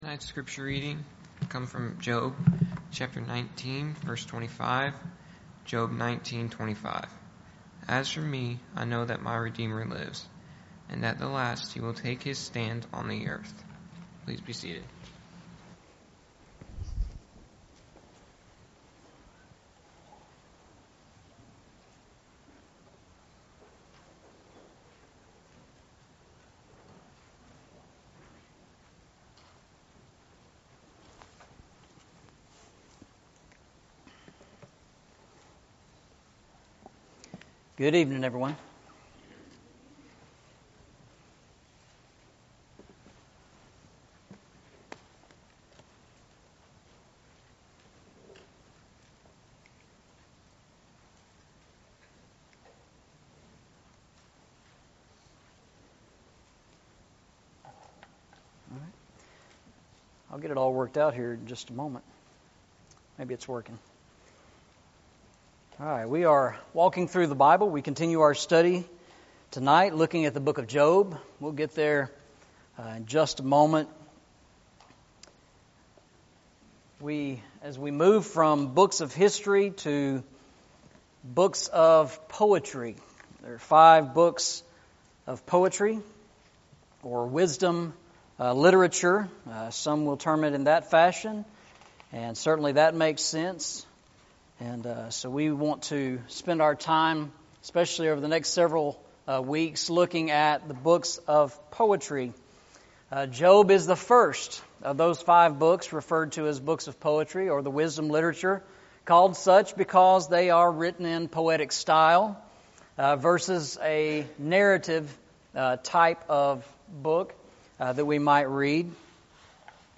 Eastside Sermons Passage: Job 19:25 Service Type: Sunday Evening « The Church